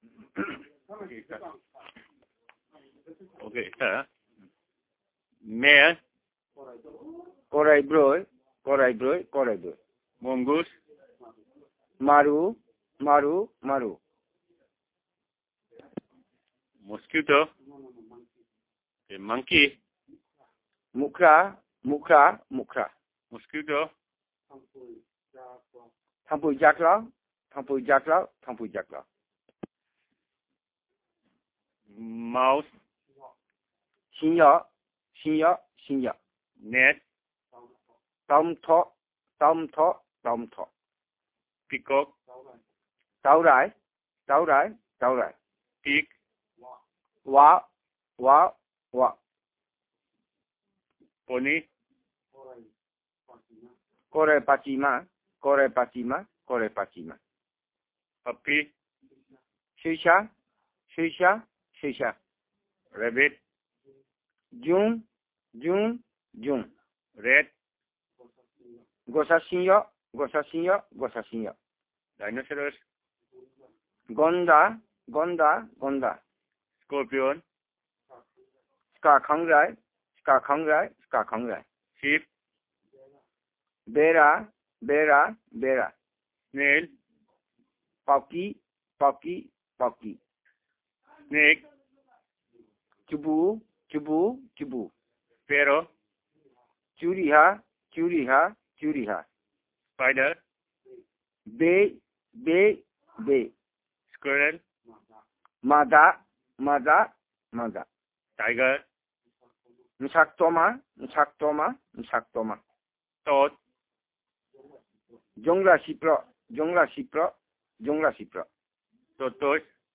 NotesThis is an elicitation of words about wild animals, birds, flies, insects and rodents.